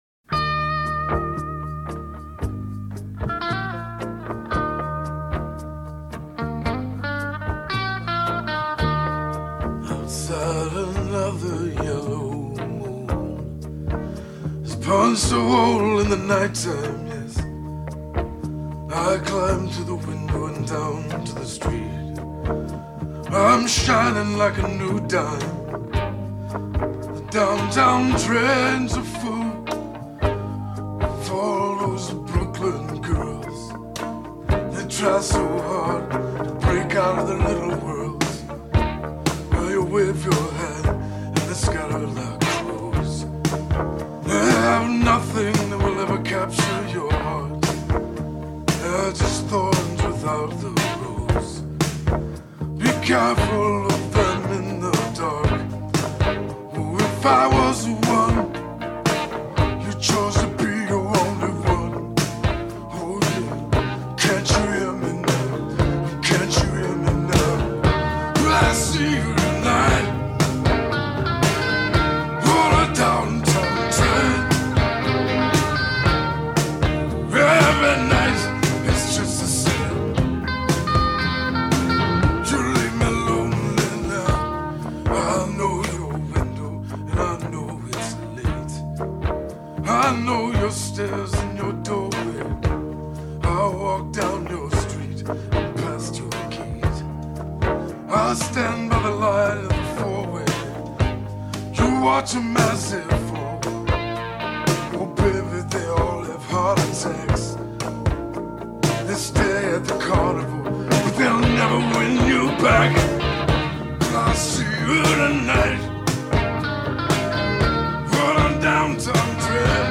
unfurls one last twanging crescendo on guitar.